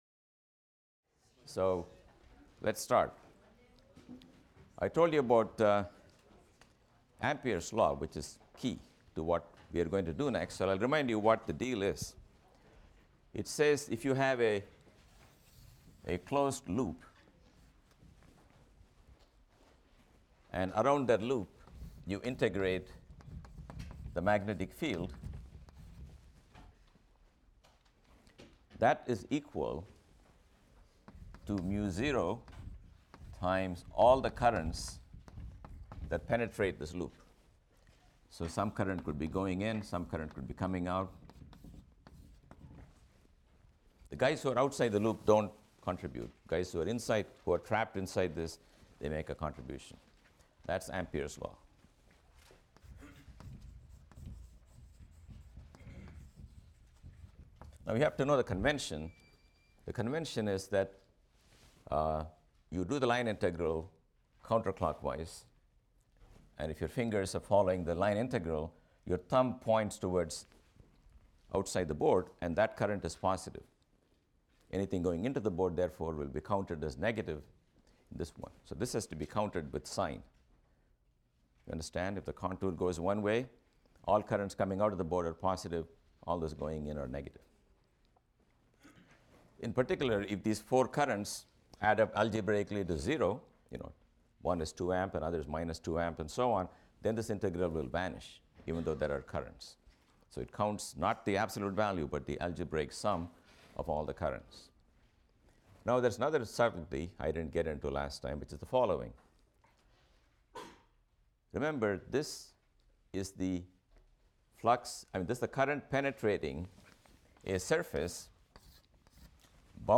PHYS 201 - Lecture 10 - Ampere’s Law | Open Yale Courses